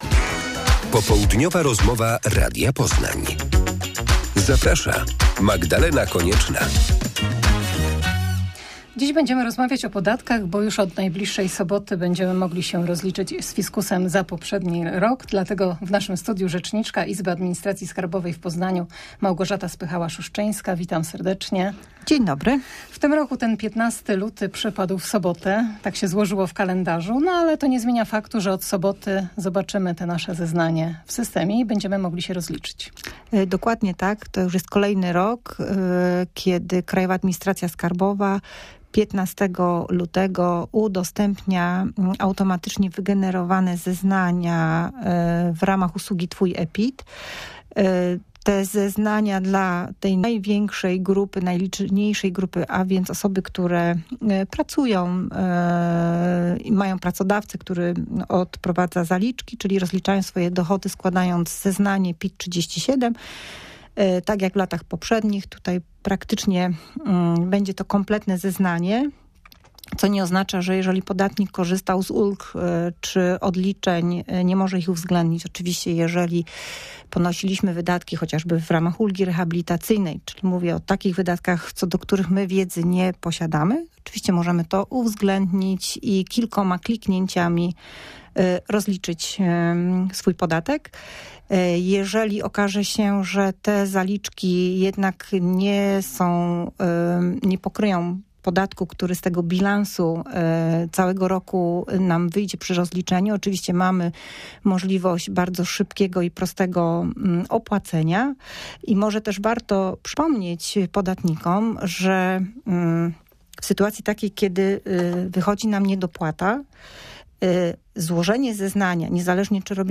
Od soboty będziemy mogli się rozliczyć z fiskusem. Rozmowa o ulgach i aplikacji Twój e-PIT.